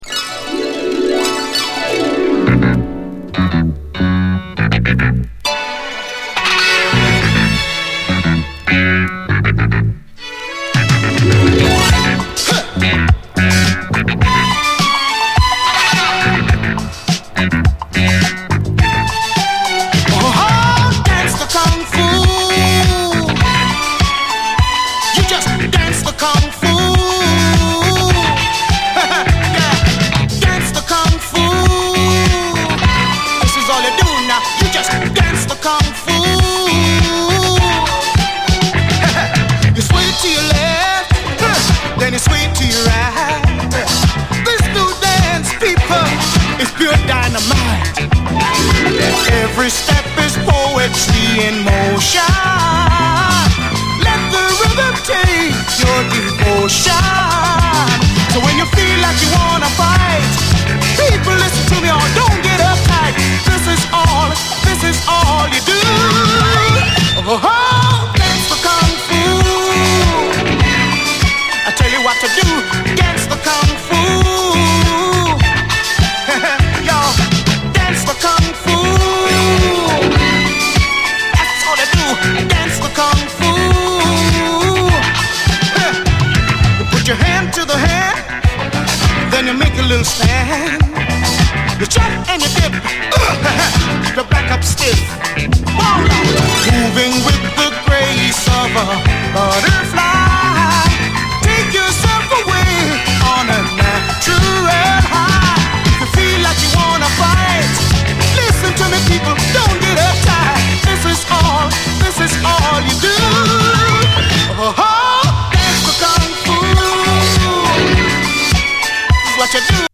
SOUL, 70's～ SOUL